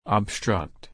/əbˈstɹʌkt/